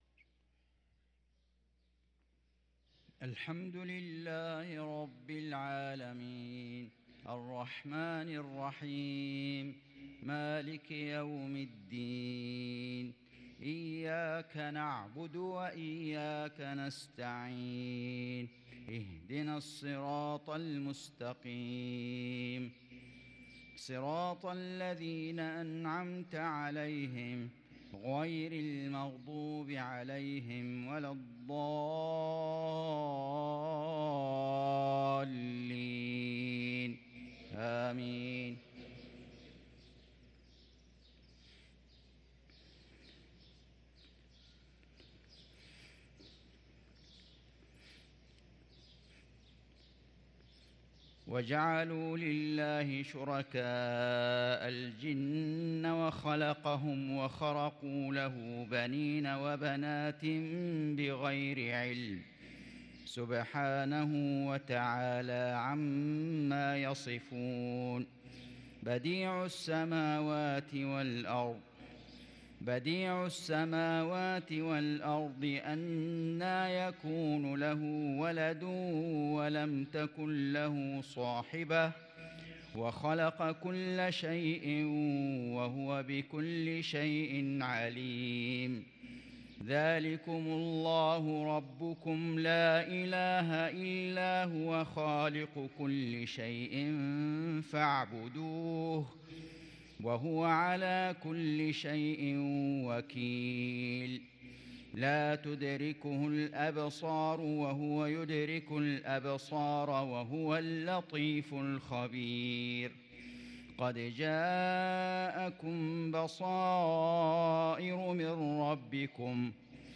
صلاة المغرب للقارئ فيصل غزاوي 7 جمادي الآخر 1444 هـ
تِلَاوَات الْحَرَمَيْن .